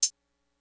Closed Hats
01 hat closed 2 hit.wav